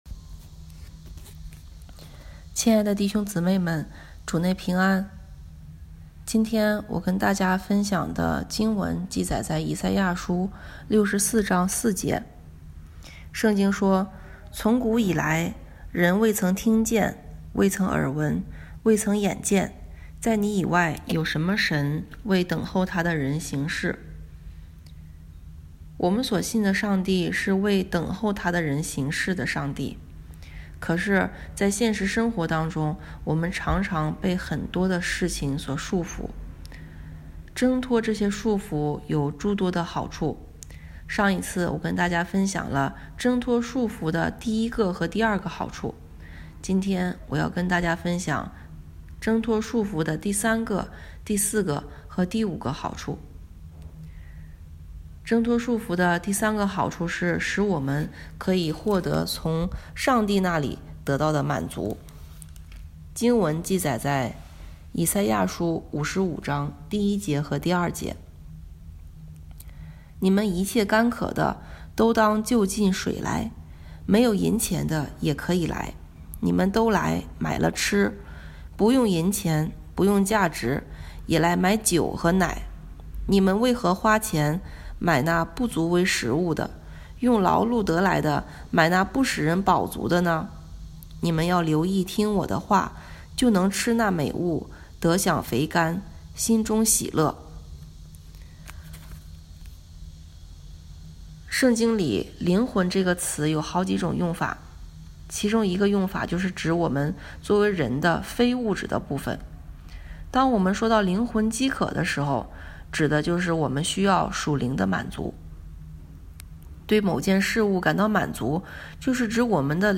题目：《挣脱束缚的好处（二）》 证道